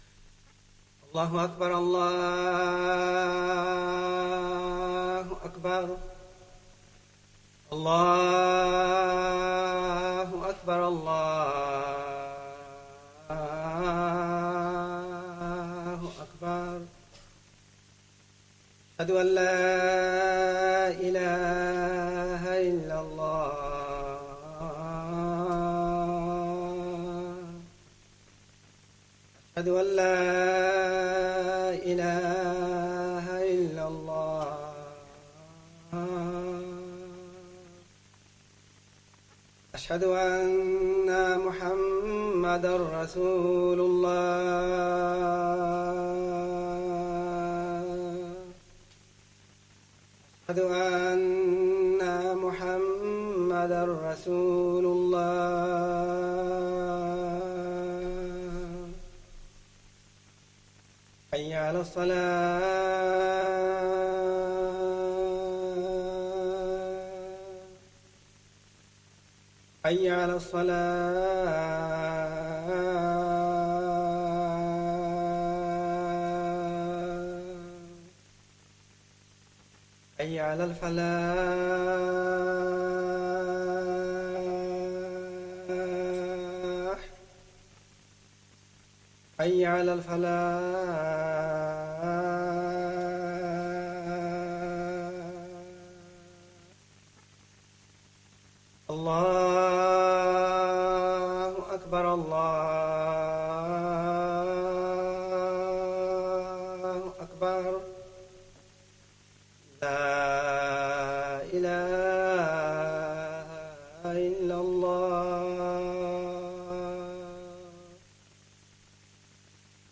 Esha Talk & Jammat